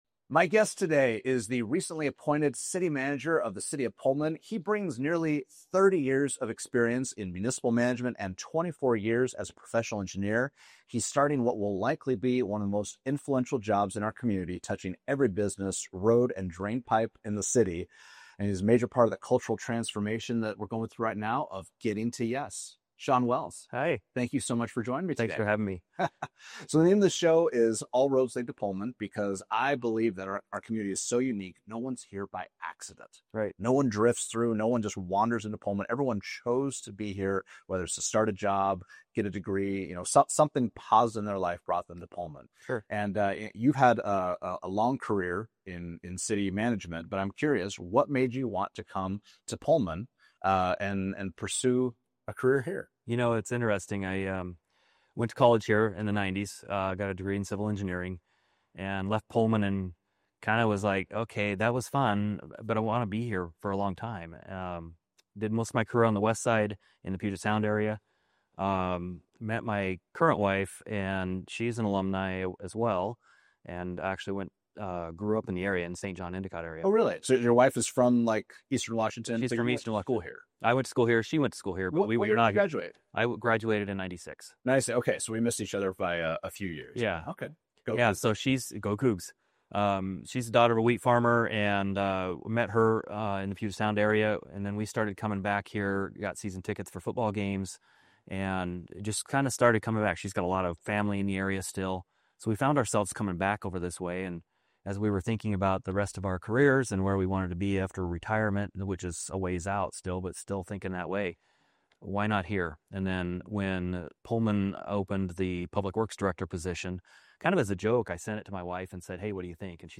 October 2, 2025 — I sat down with new city administrator Sean Wells to talk about what it really takes to run Pullman. From rebuilding downtown to rebuilding trust, we dig into leadership, community, and the fine art of keeping a small town moving forward without losing what makes it home.